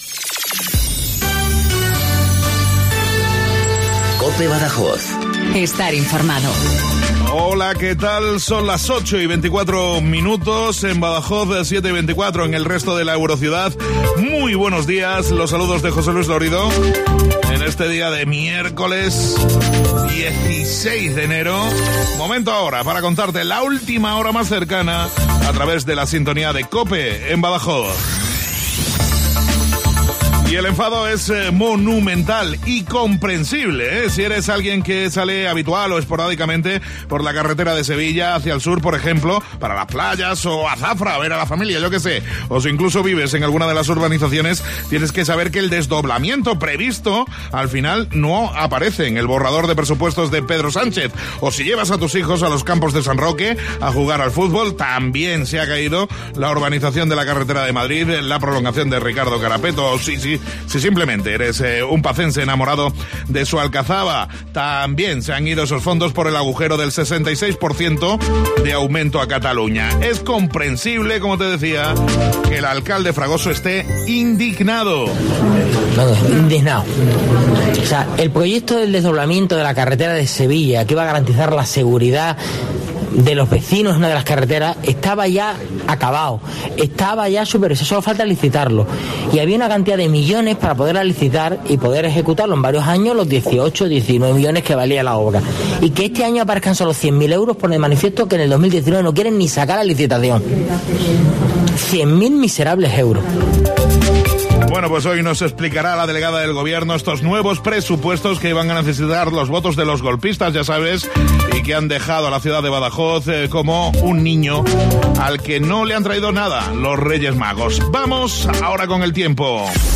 INFORMATIVO LOCAL BADAJOZ 0824